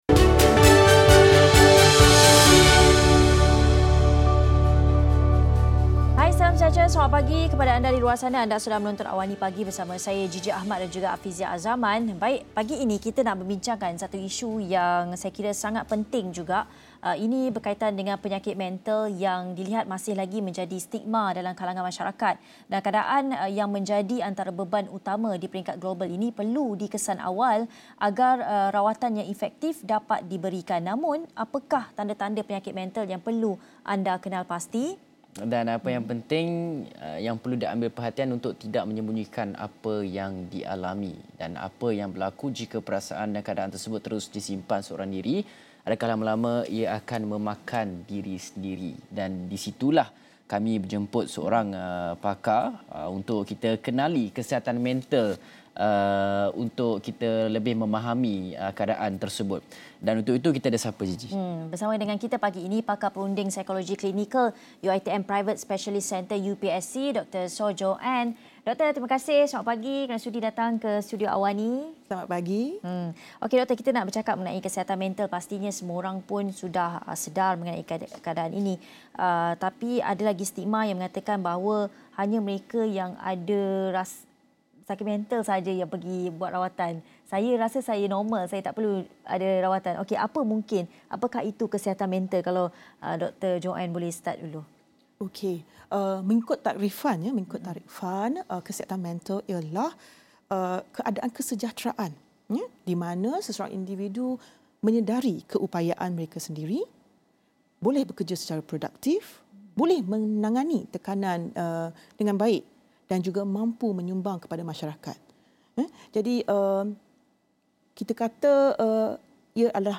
Ikuti diskusi 8.30 pagi